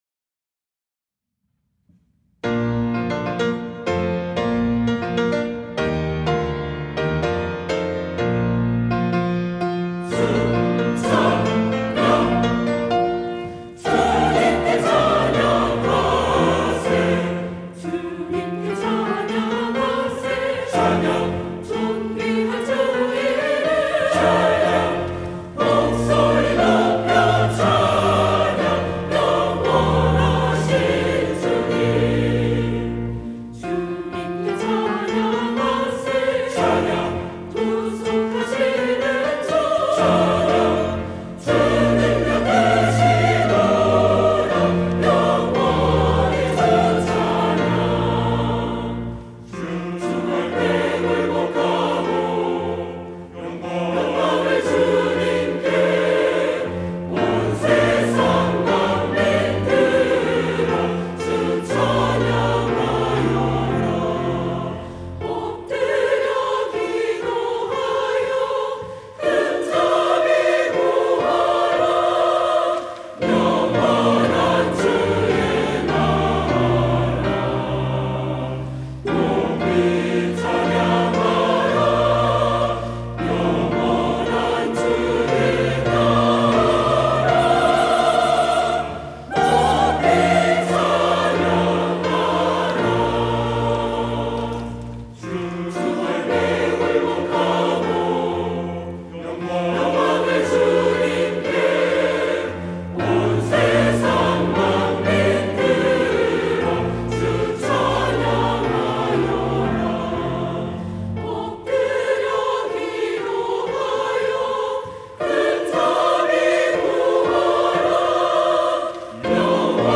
시온(주일1부) - 주님께 찬양하세
찬양대